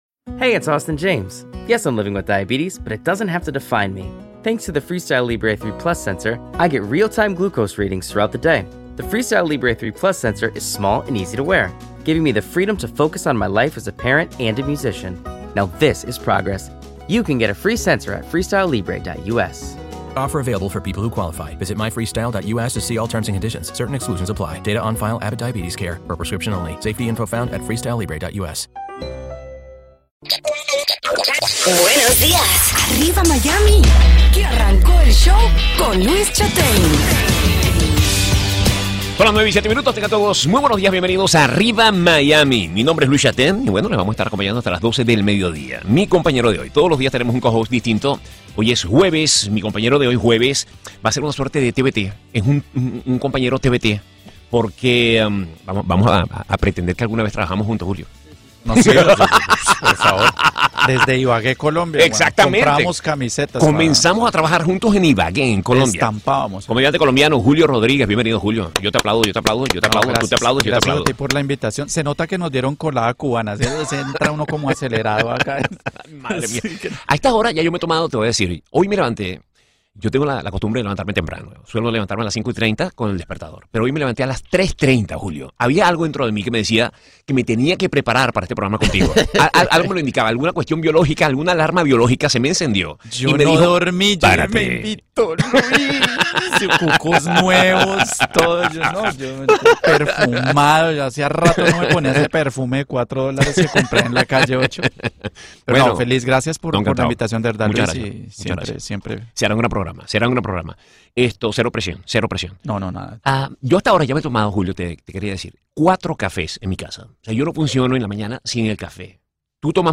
Arriba Miami" transmitido por Exitos 107.1FM